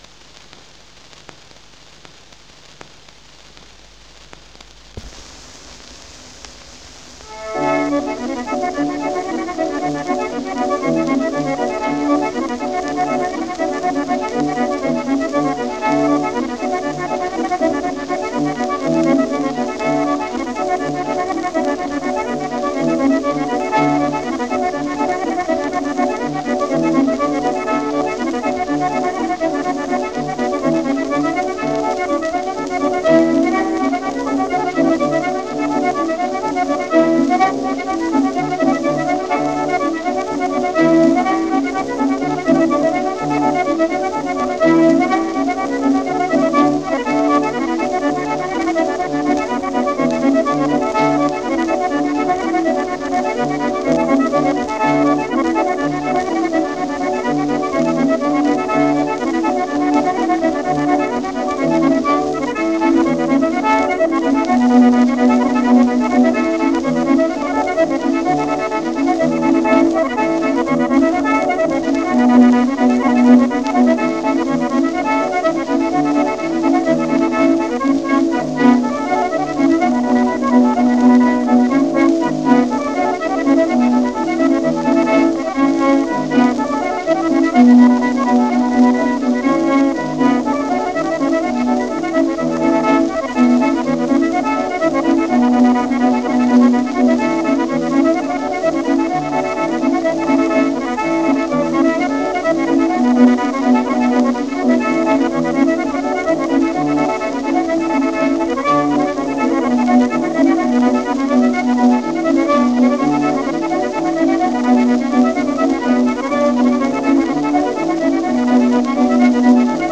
Medley of Irish Reels n 8.wav